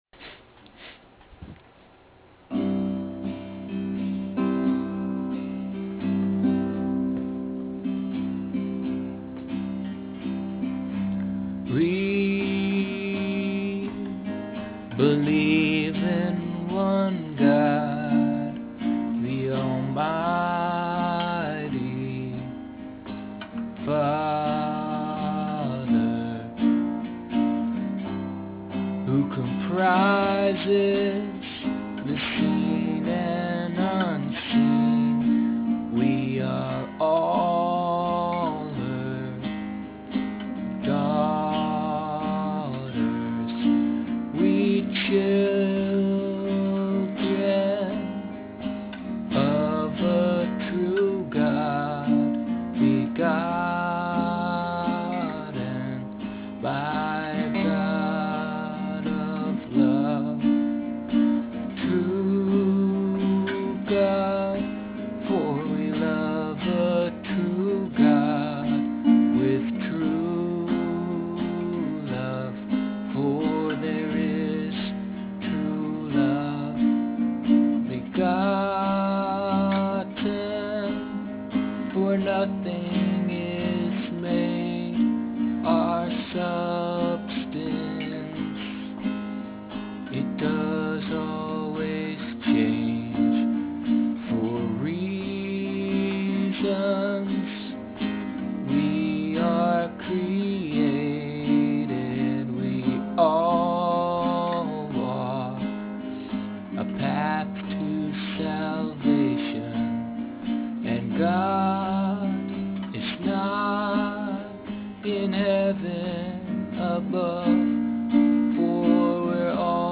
E, C, G, D, Am, C, G, D